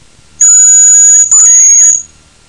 Звуки рябчика
Звук манка для охоты на рябчика